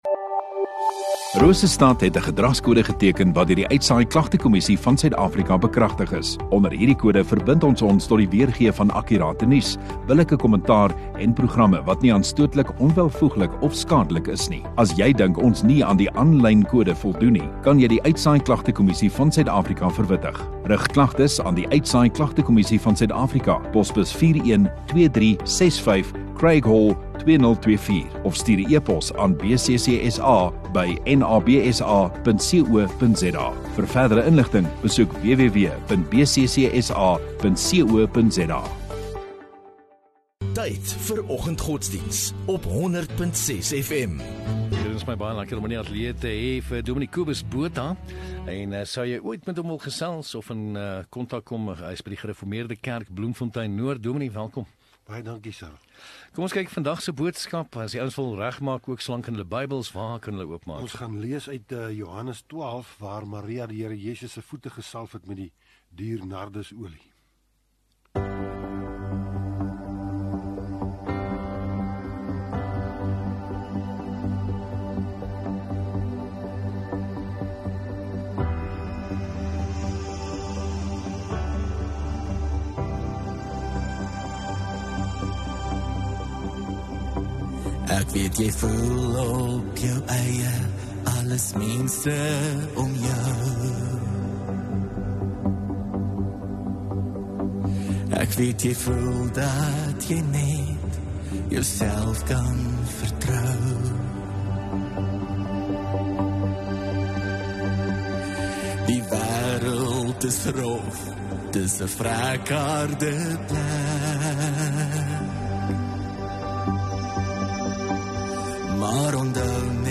16 Apr Dinsdag Oggenddiens